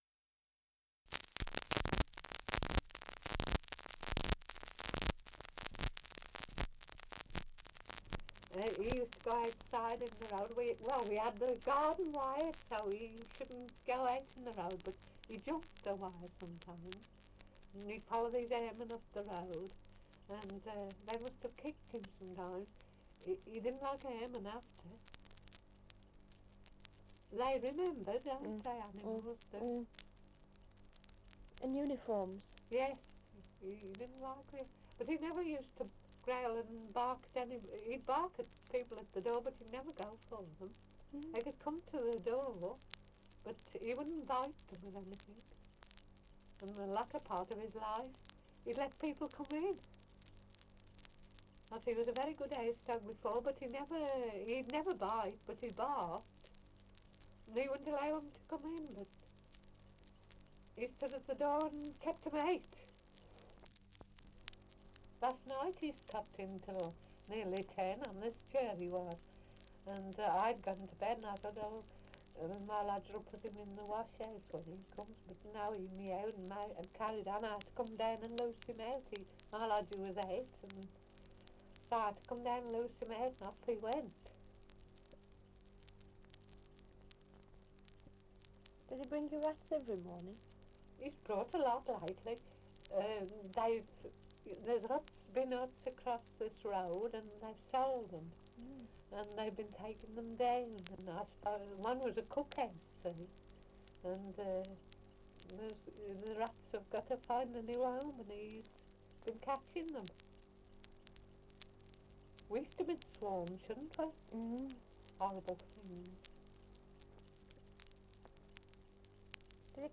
Dialect recording in Albrighton, Shropshire
78 r.p.m., cellulose nitrate on aluminium